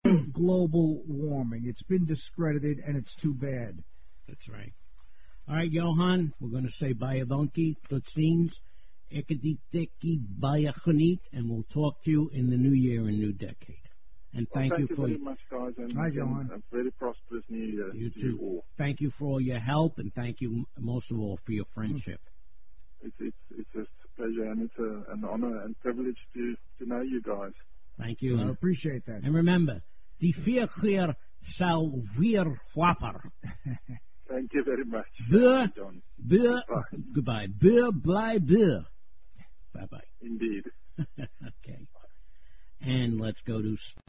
Tags: South Africa Propaganda Apartheid The Right Perspective Talk Radio